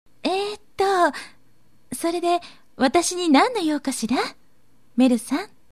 隣街に住んでいる、感じの良い女の人。
サンプルボイス：